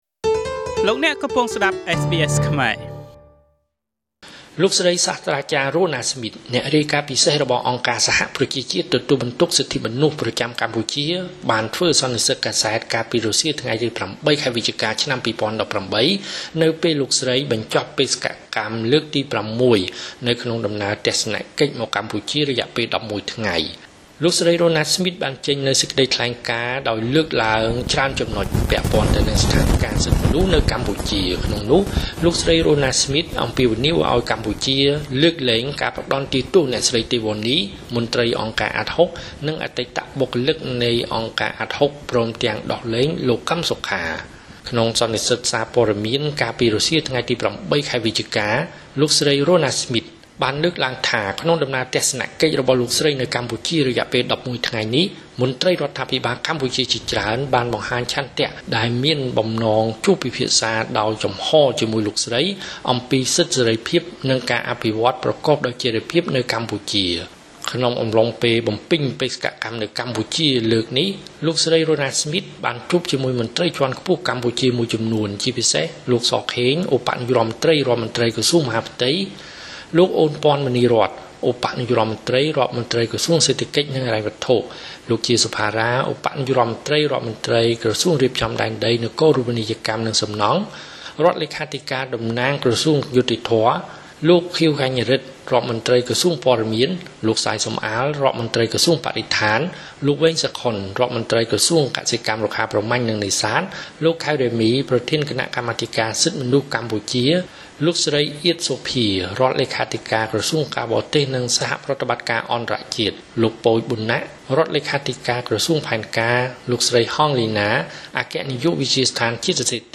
លោកស្រី សាស្ត្រាចារ្យ រ៉ូណា ស្មីត អ្នករាយការណ៍ពិសេសរបស់អង្គការសហប្រជាជាតិ ទទួលបន្ទុកសិទ្ធិមនុស្ស ប្រចាំកម្ពុជា បានធ្វើសន្និសីទកាសែត កាលពីរសៀលថ្ងៃទី០៨ ខែវិច្ឆិកា ឆ្នាំ២០១៨ នៅពេលលោកស្រី បញ្ចប់បេសកកម្ម ក្នុងដំណើរទស្សនកិច្ចមកកម្ពុជា រយៈពេល ១១ថ្ងៃ។